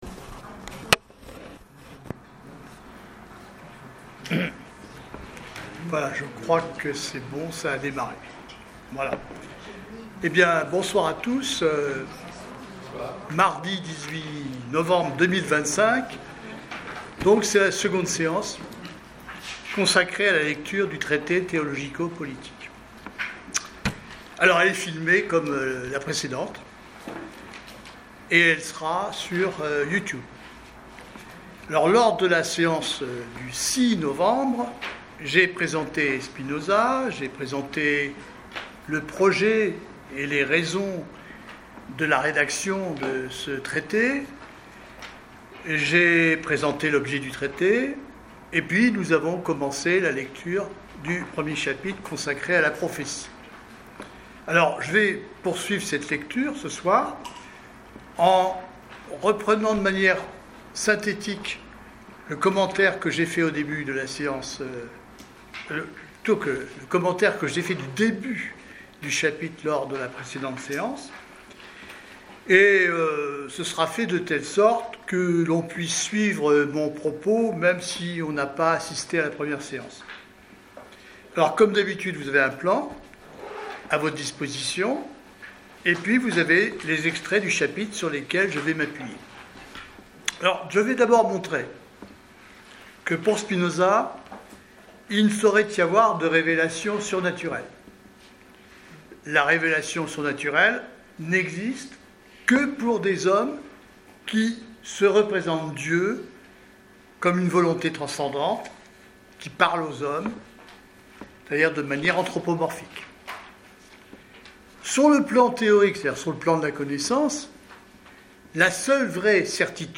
Lecture du Traité théologico-politique de Spinoza
1/ Enregistrement de la séance du 18 novembre 2025